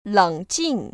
冷静[ lěngjìng ]